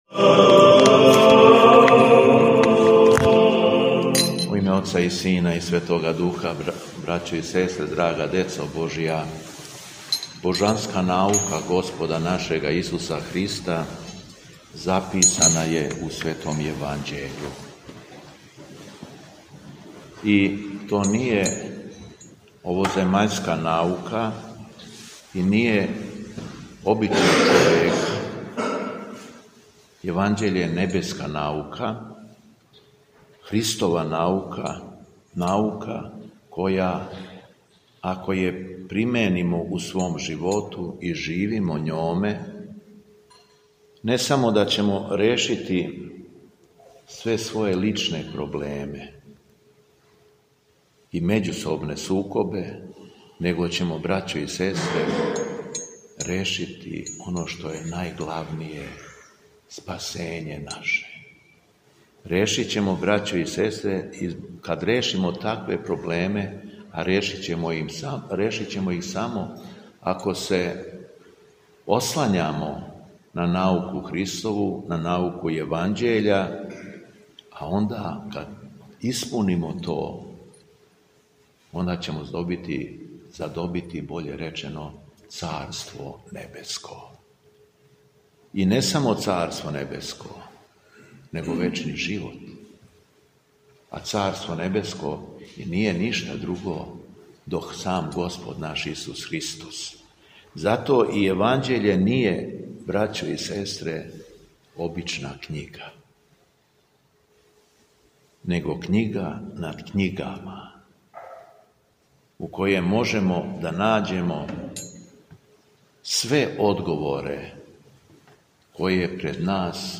СВЕТА АРХИЈЕРЕЈСКА ЛИТУРГИЈА У ХРАМУ СВЕТОГ КНЕЗА ЛАЗАРА У БЕЛОШЕВЦУ
Беседа Његовог Високопреосвештенства Митрополита шумадијског г. Јована